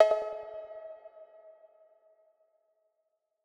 Yung Gud Bell.wav